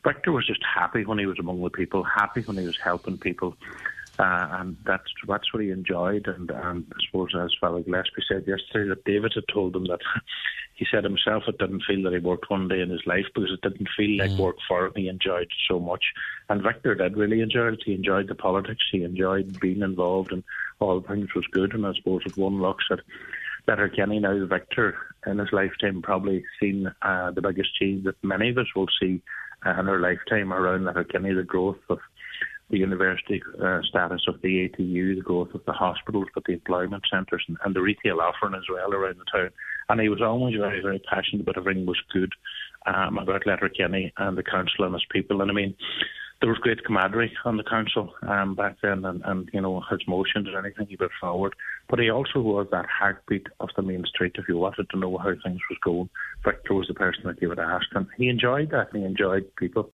Councillor Ciaran Brogan says Mr Fisher witnessed some of the biggest changes Letterkenny will ever experience: